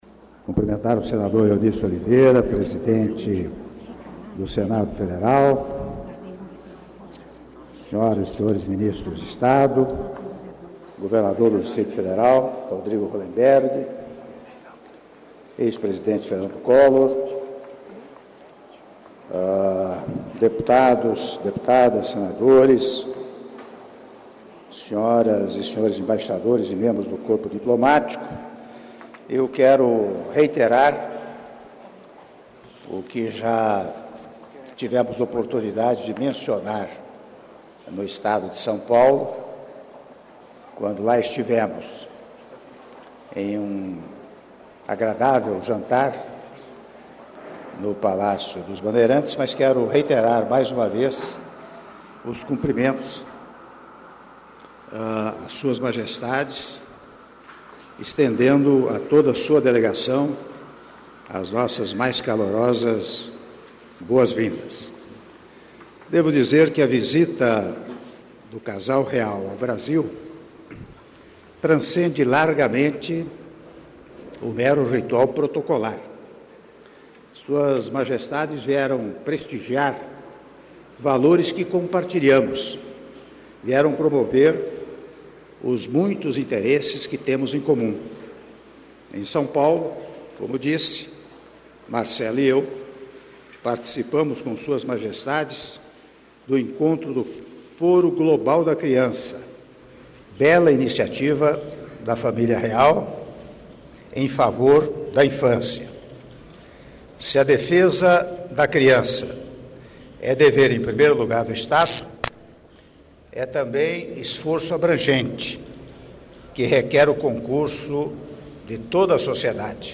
Áudio do brinde do Presidente da República, Michel Temer, durante almoço em homenagem ao Rei e Rainha da Suécia - Brasília/DF (06min22s)